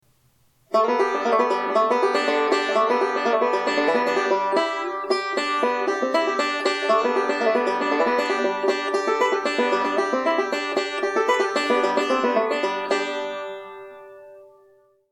Gold Tone AC-1 Banjo w/gigbag - $299 + $65 S/H/I (US only)
Even as an open back, this banjo has a nice tone!